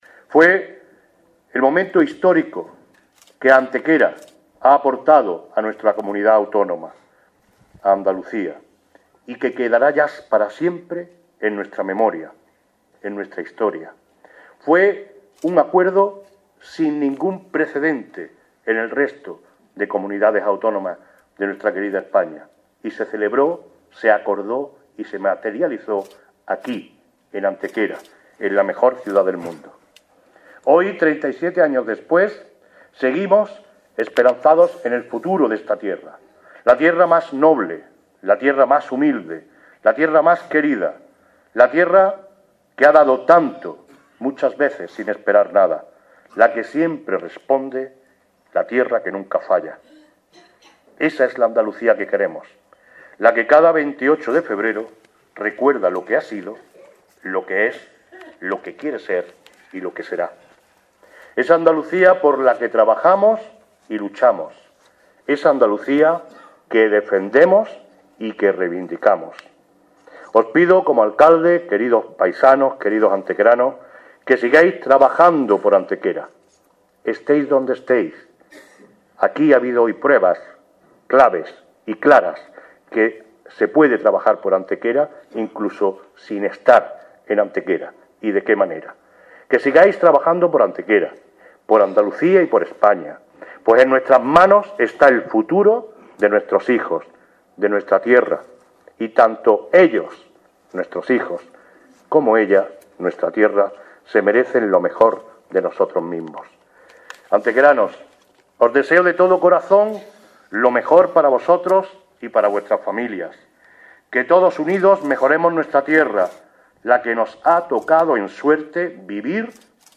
Además, el Alcalde de Antequera realizó su habitual discurso institucional aludiendo al espíritu del Pacto de Antequera, hito en la historia local que contribuyó de forma decisiva a la configuración de nuestra comunidad autónoma como tal. Cortes de voz M. Barón (1) 849.53 kb Formato: mp3 M. Barón (2) 986.61 kb Formato: mp3